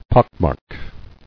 [pock·mark]